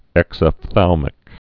(ĕksəf-thălmĭk)